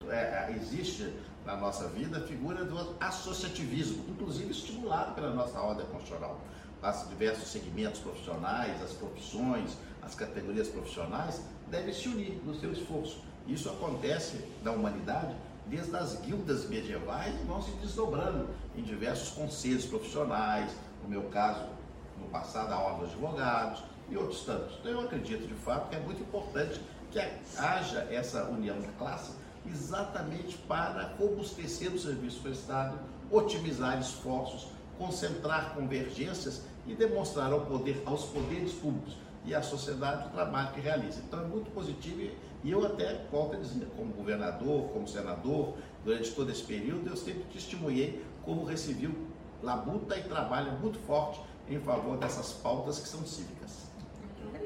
No dia 25 de março, a diretoria do Recivil recebeu em sua sede o ministro do Tribunal de Contas da União (TCU), Antônio Augusto Anastasia, em uma visita de cortesia.
Na ocasião, Anastasia concedeu uma entrevista ao Sindicato, na qual pode expor sua percepção e apoio a classe dos registradores civis mineiros.
ENTREVISTA-ANASTASIA_audio-online-audio-converter.com_.mp3